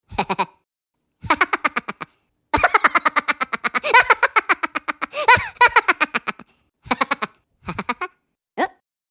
haha-minion-laugh_14127.mp3